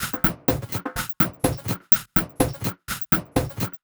tx_perc_125_warped1.wav